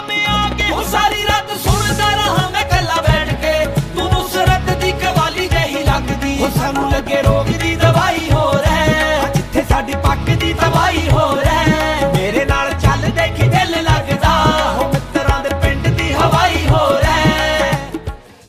Ringtone File